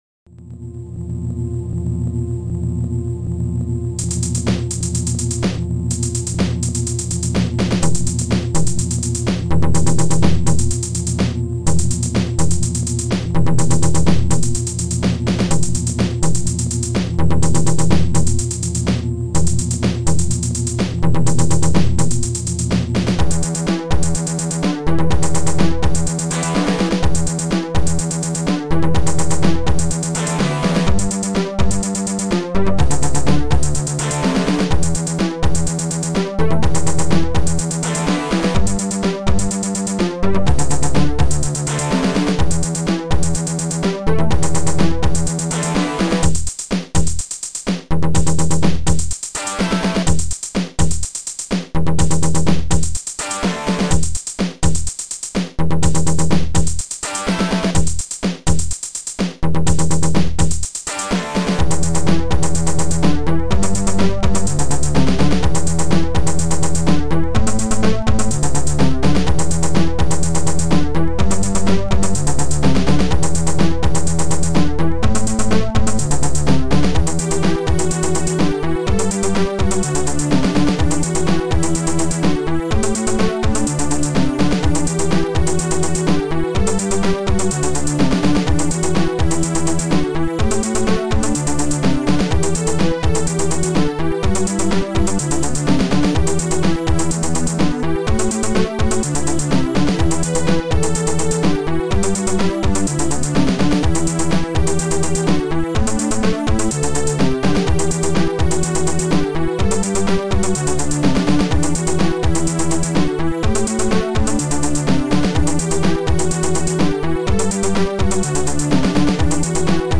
Soundtracker 15 Samples